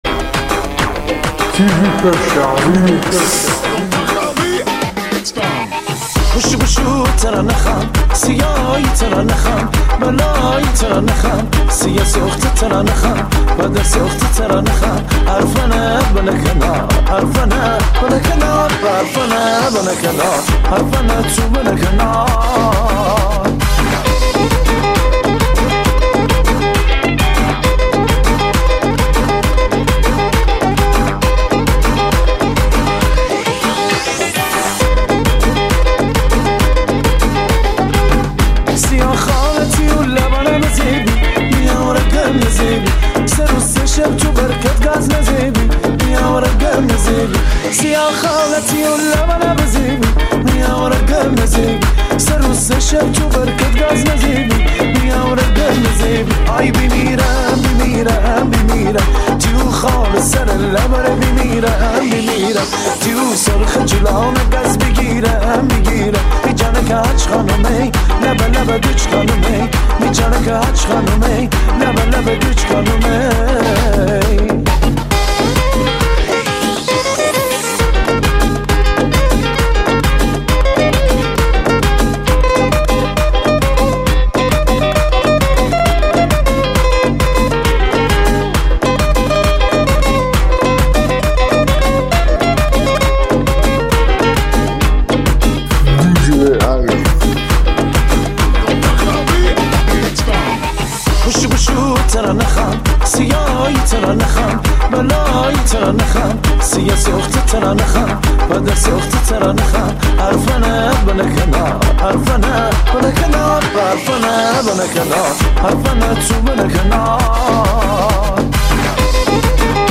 ریمیکس شاد تریبال رقصی
ریمیکس شاد تریبال قر کمری